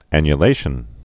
(ănyə-lāshən)